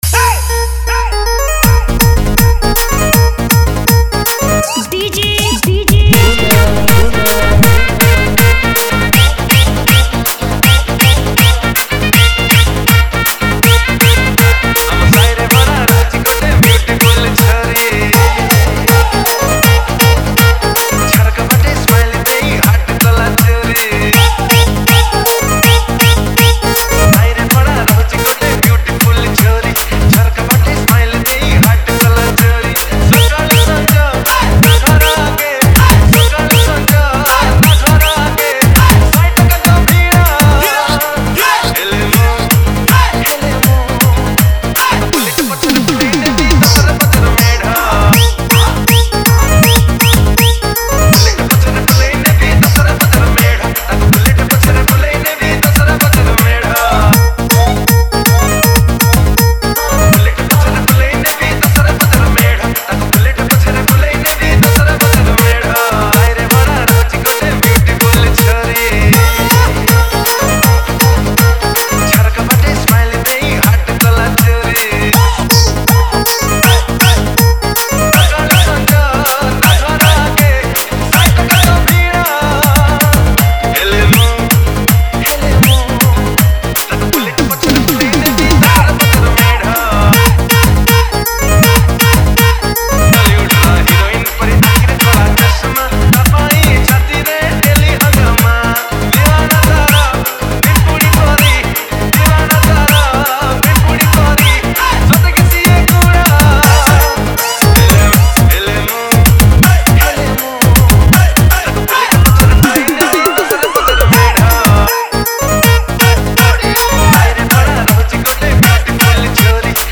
Category:  Odia New Dj Song 2019